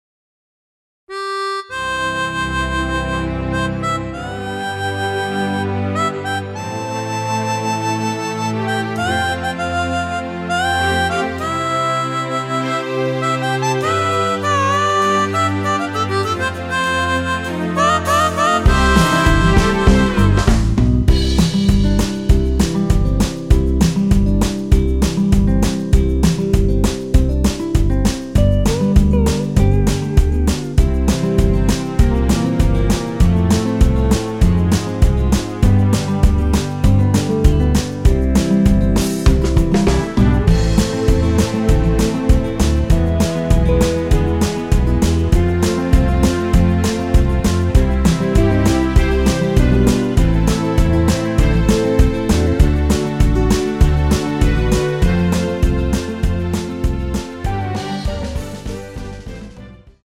전주가 길어서 8마디로 편곡 하였으며
원키(1절+후렴)으로 진행되는MR입니다.
앞부분30초, 뒷부분30초씩 편집해서 올려 드리고 있습니다.
중간에 음이 끈어지고 다시 나오는 이유는